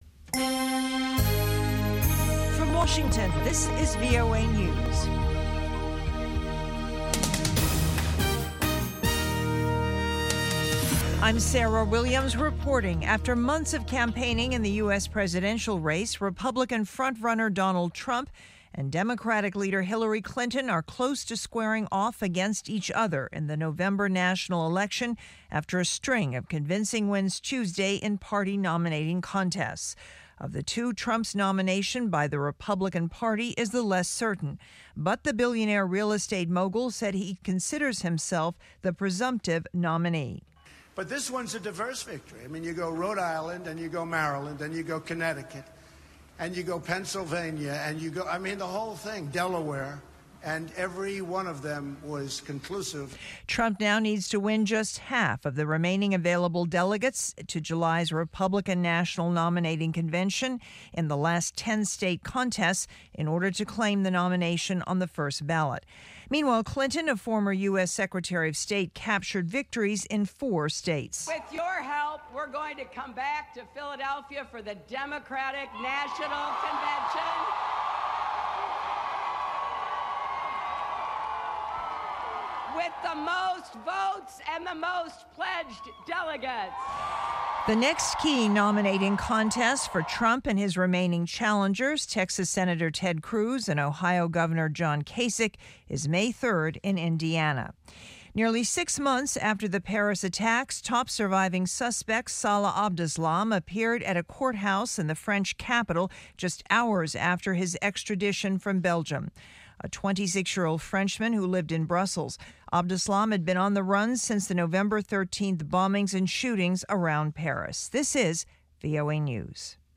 1600 UTC Hourly Newscast for April 27, 2016